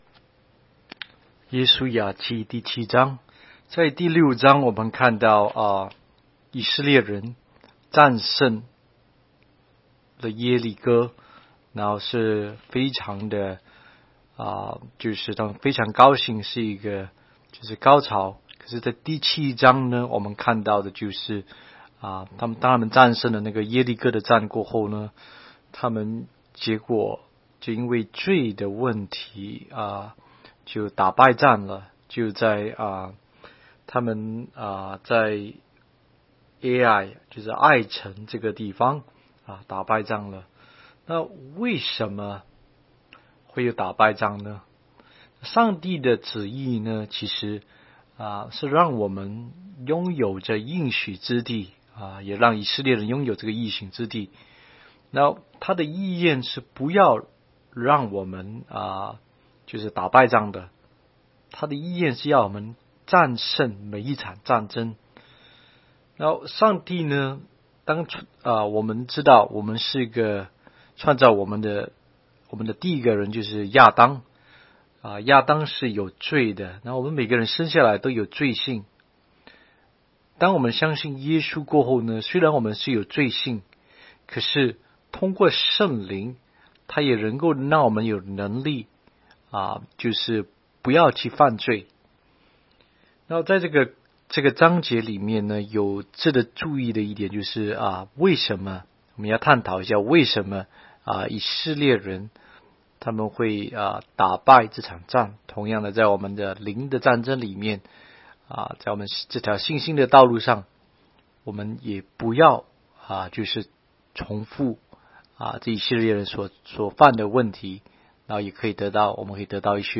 16街讲道录音 - 每日读经-《约书亚记》7章